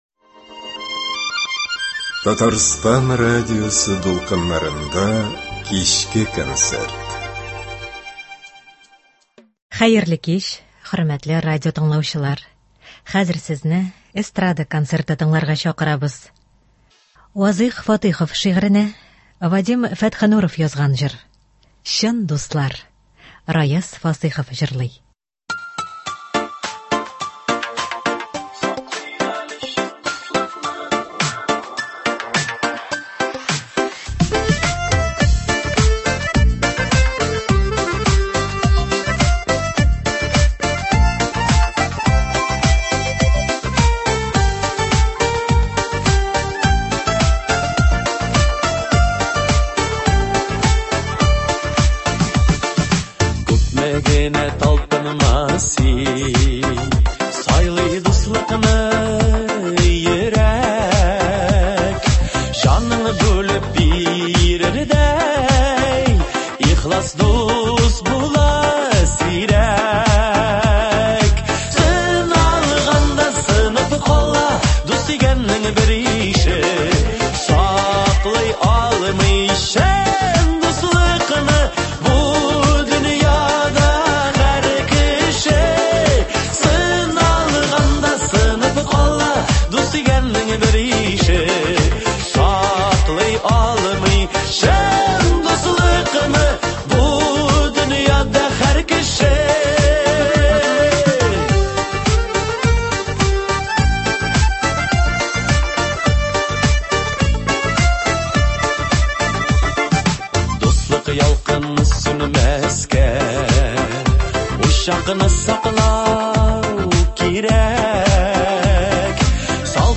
Эстрада музыкасы концерты.